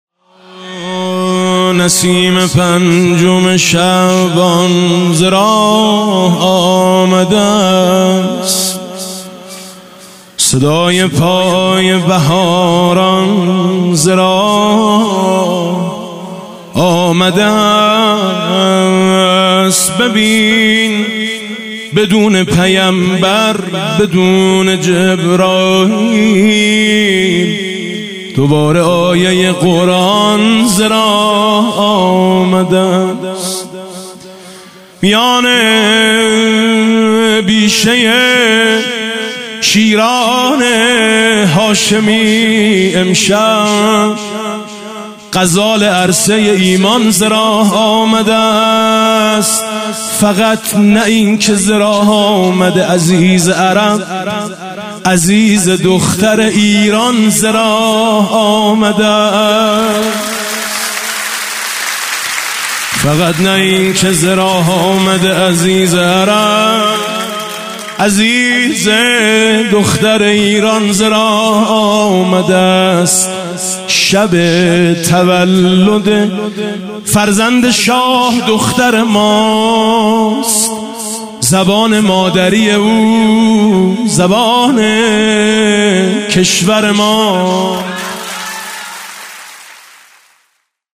میلاد سرداران کربلا
مدح حاج میثم مطیعی